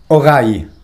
1. pus sovint prononcî come e francès «orèye», pacô avou on lon A «orâye».